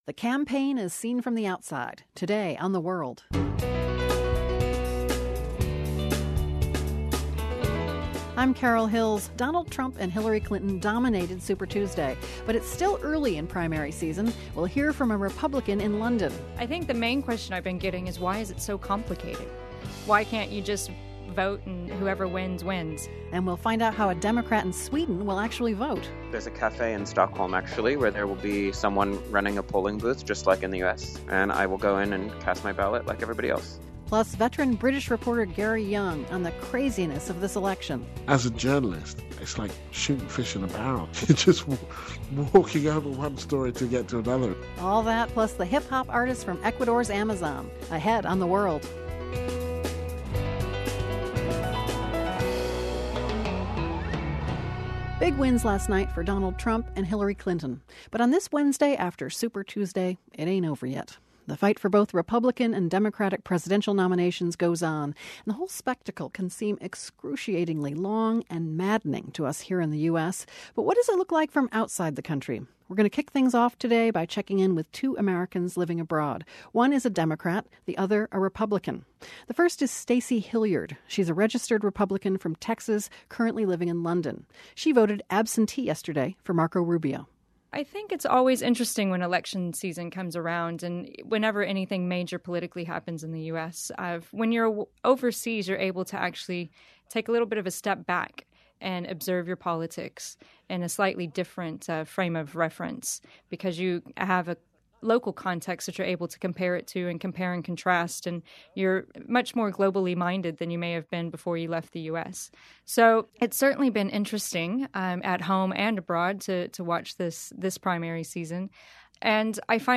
First we hear about the big wins last night for Donald Trump and Hillary Clinton, and get the view from overseas with two ex-pat voters — a Republican living in London, and a Democrat living in Stockholm. We'll also hear about what the Centers for Disease Control are doing to combat the Zika virus, particularly here in the United States. Finally, we bring you the tale of an Ecuadorian star runner turned hip-hop musician.